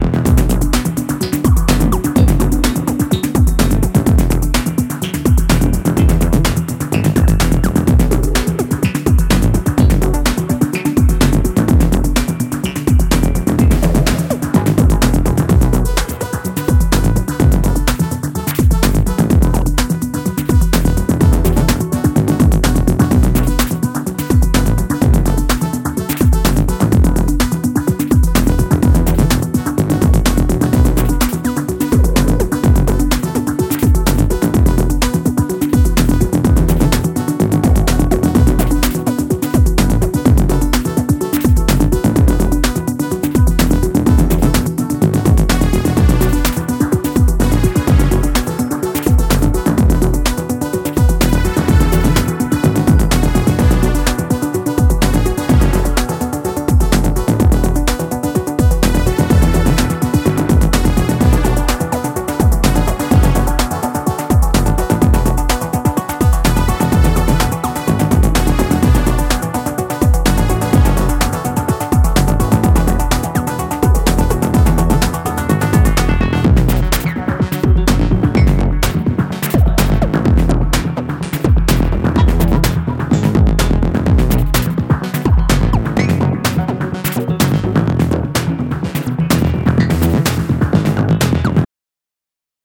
骨太なビートにダビーなアルペジオが被るサイケデリックナンバー